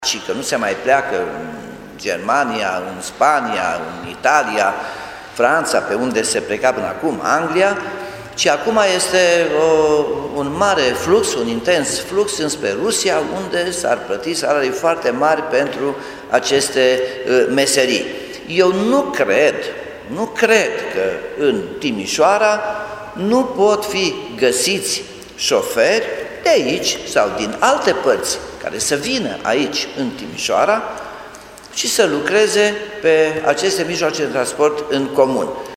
Primarul Nicolae Robu a declarat că principala responsabilitate pentru problemele de la RATT o poartă managementul, de la care așteaptă soluții.